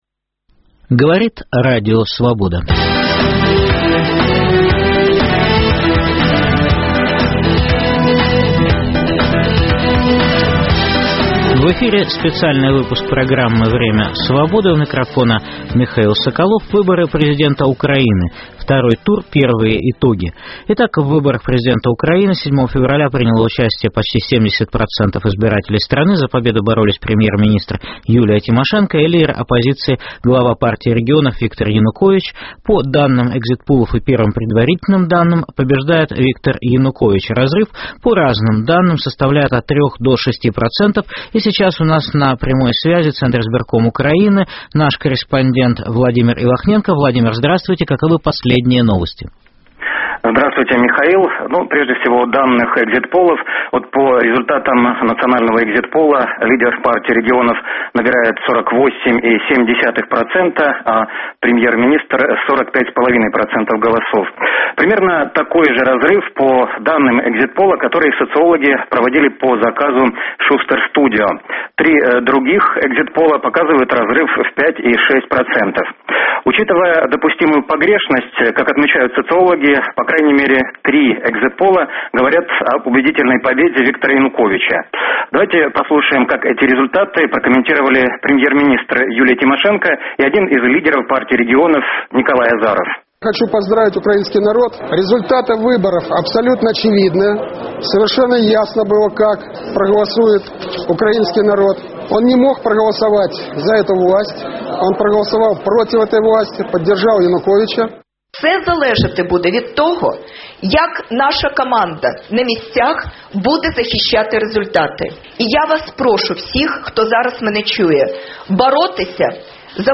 Программы посвящена второму туру президентских выборов на Украине. Репортажи: из ЦИКа о первых результатах выборов, из регионов Украины.